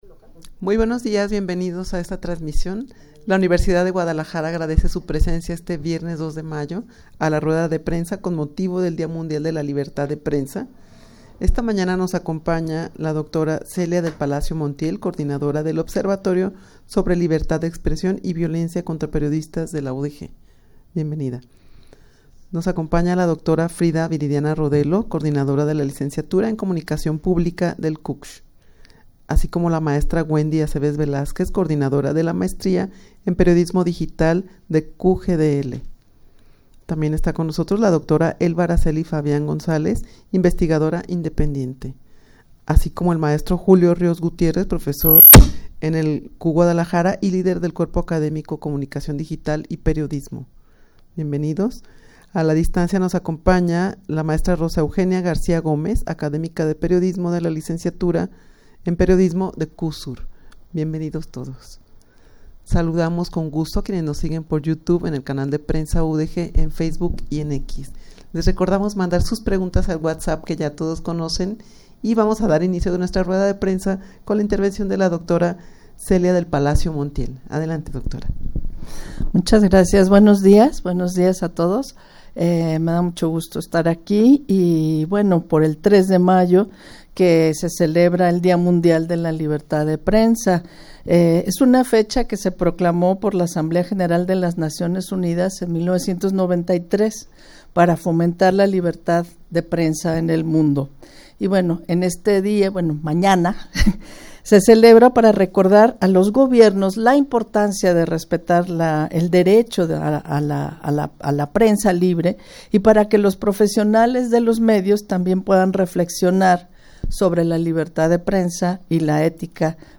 Audio de la Rueda de Prensa
rueda-de-prensa-con-motivo-del-dia-mundial-de-la-libertad-de-prensa.mp3